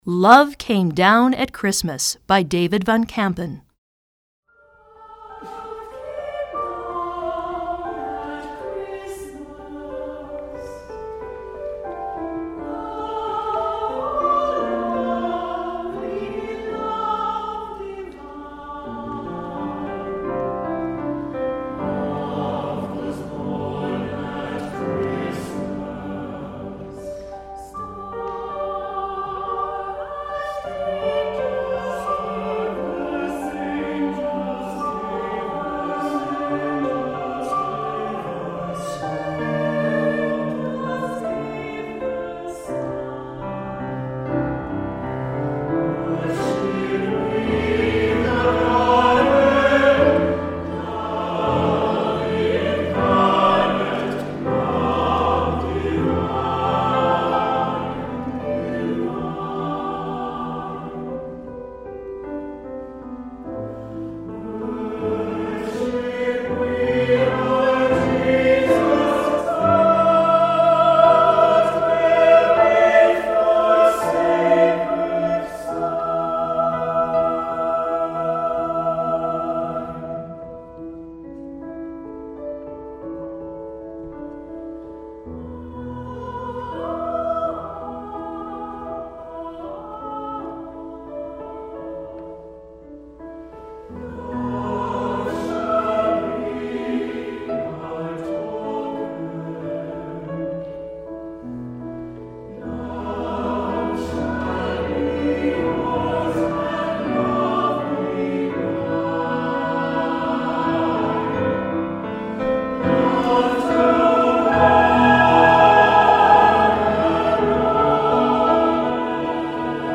Choeur Mixte (SATB) et Piano